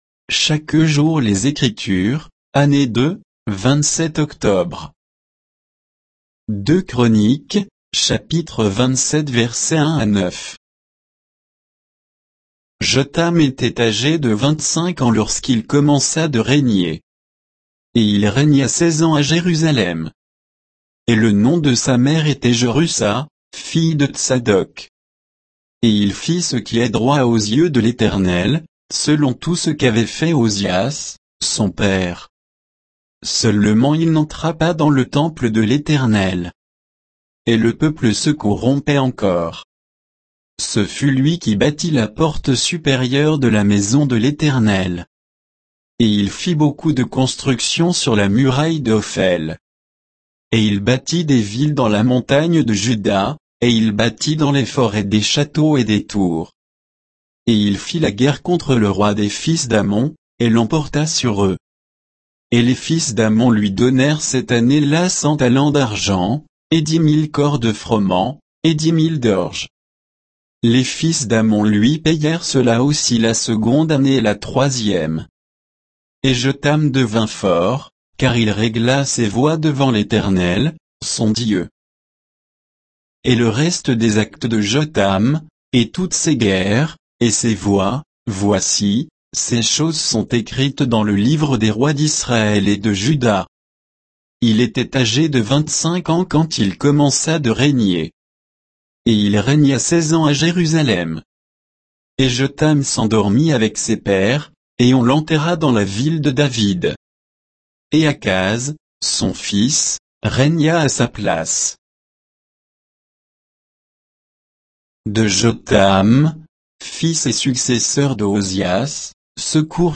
Méditation quoditienne de Chaque jour les Écritures sur 2 Chroniques 27